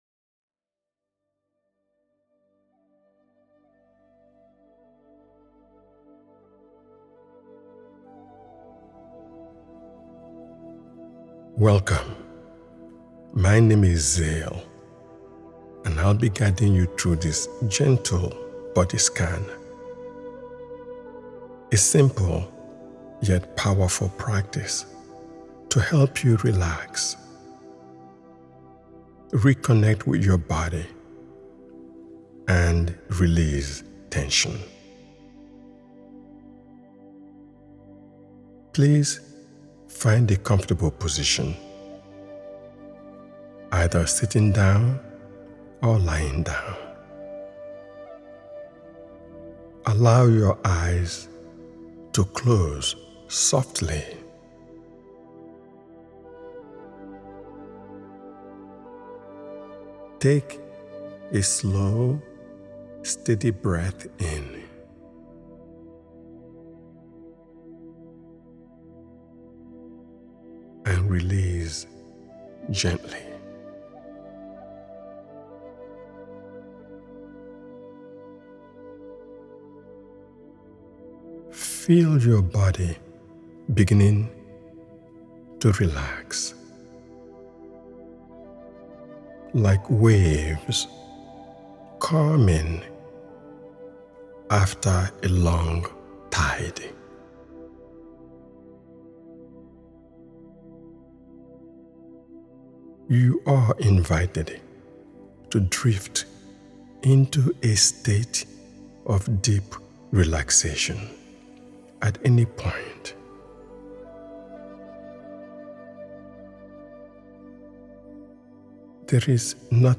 Gentle Body Scan For Beginners: Relax And Release Tension guides you through mindful breathing and gentle body awareness, helping you release stress, ease anxiety, and restore inner calm. This beginner-friendly meditation supports restful sleep, emotional balance, and overall relaxation, allowing your mind and body to settle into stillness, one gentle breath at a time.